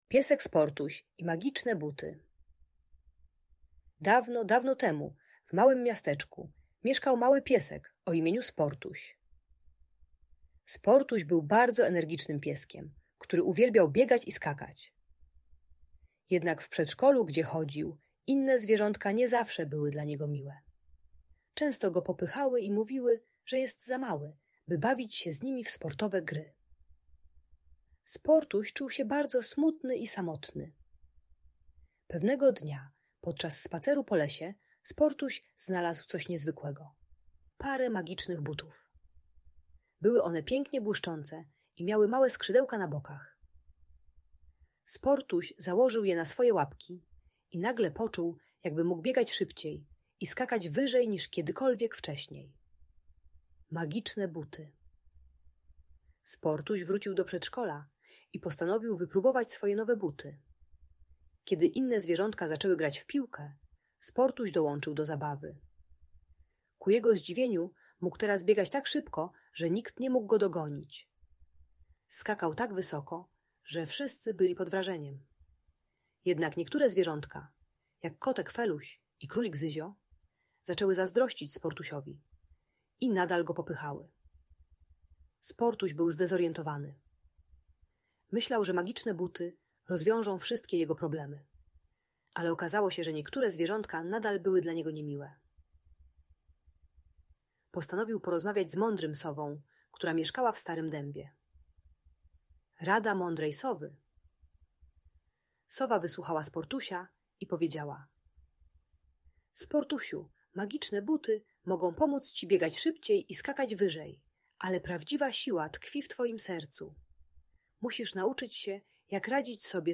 Piesek Sportuś i Magiczne Buty - Audiobajka